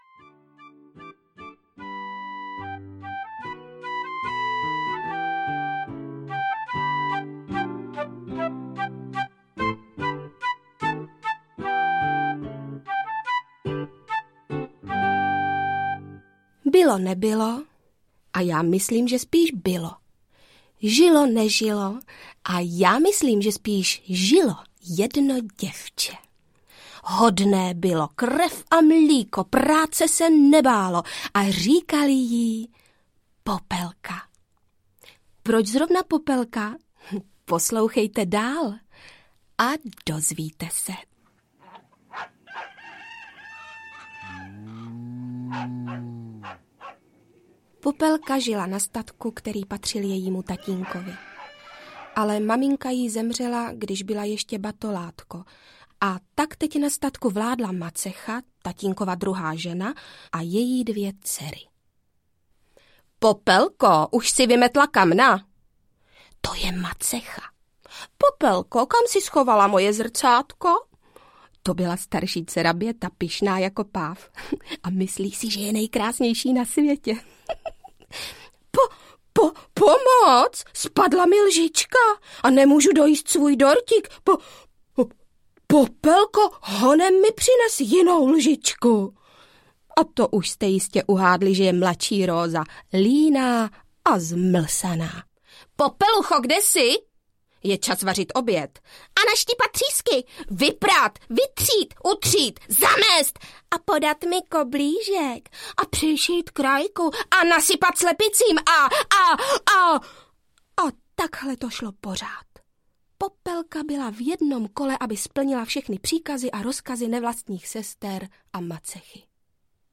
Pohádky audiokniha
Nyní je vypravěčkou dvou příběhů, mezi kterými je na prvním místě právě pohádka "O Popelce"
Ukázka z knihy